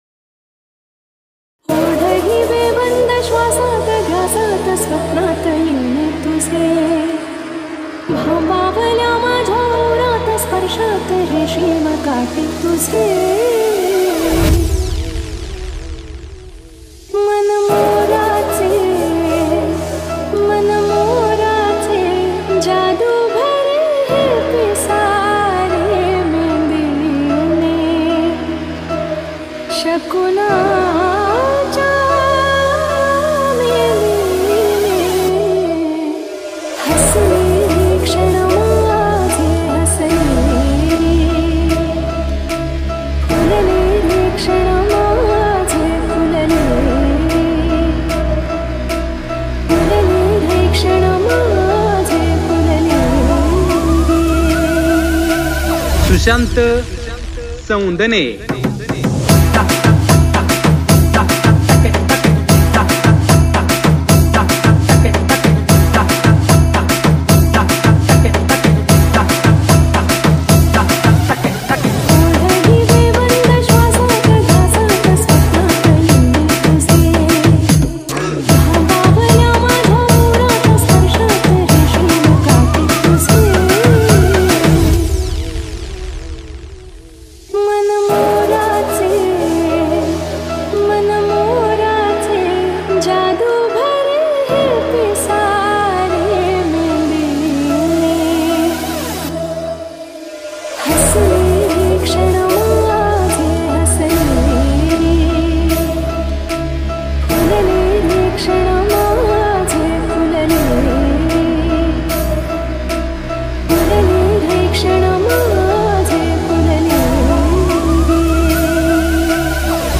• Category: MARATHI SOUND CHECK